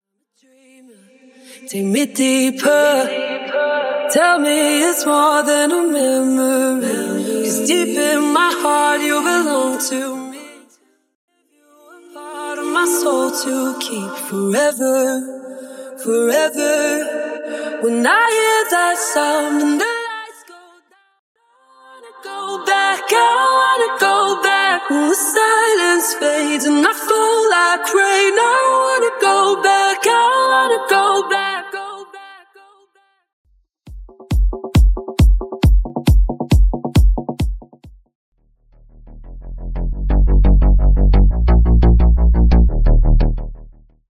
(Bassline Stem)
(Percussion & Drums Stem)